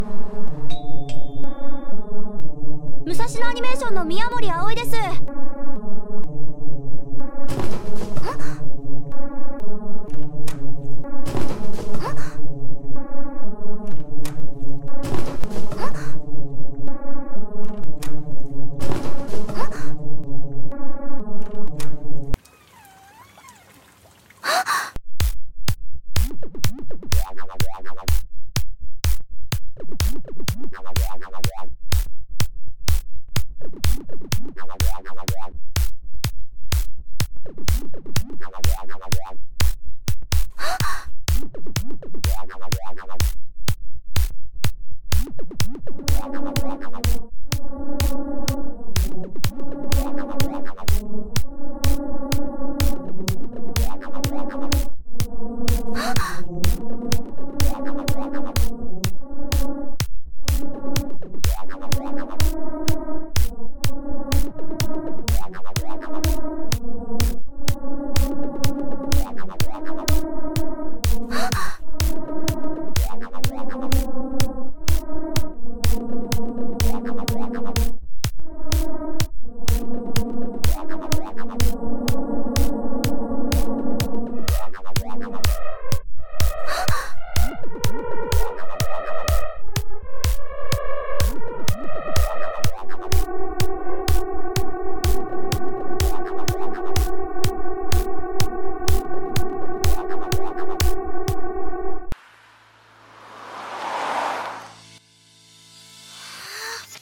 Noises made in SunVox, vocals and mixing in Audacity. The vocals and some effects are from Shirobako ep1.